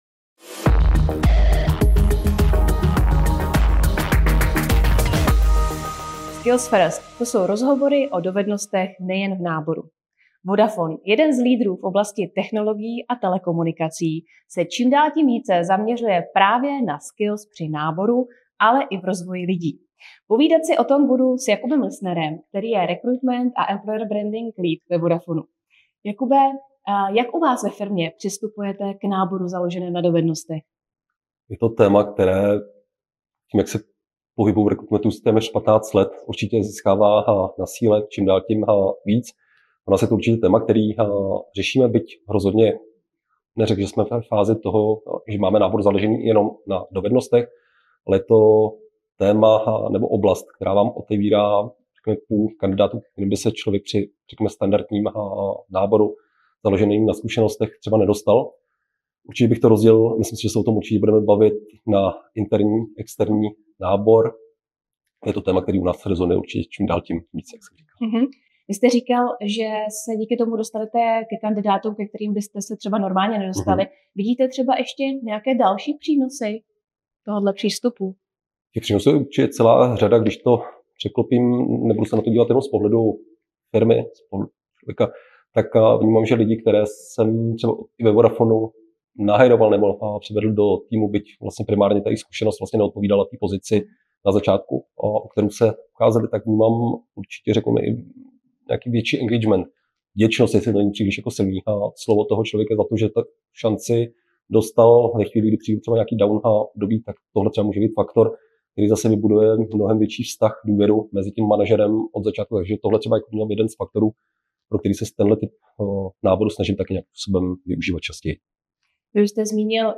Celý rozhovor si můžete poslechnout i na našem YouTube kanálu: